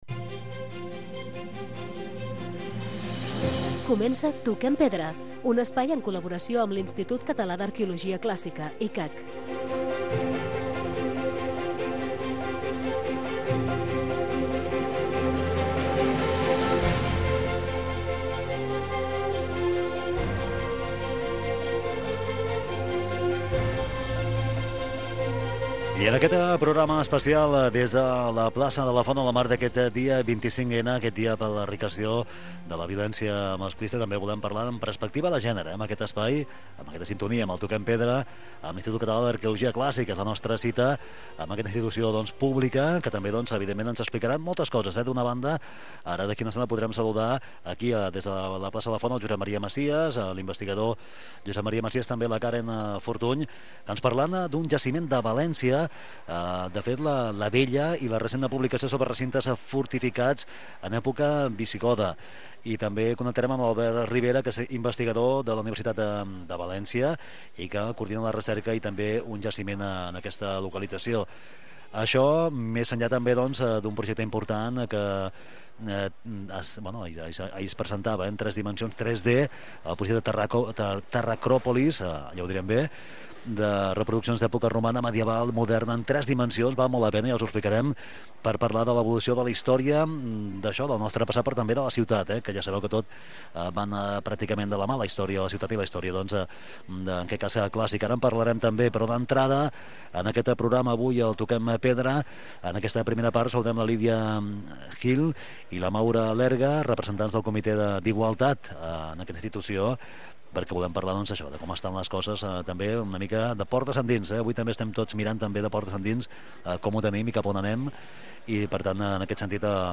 Toquem pedra. Especial del 25N des de la plaça de la Font